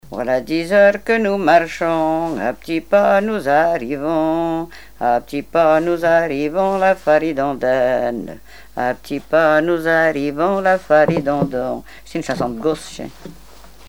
Fonction d'après l'analyste gestuel : à marcher
Genre énumérative
Répertoire de chansons traditionnelles et populaires
Pièce musicale inédite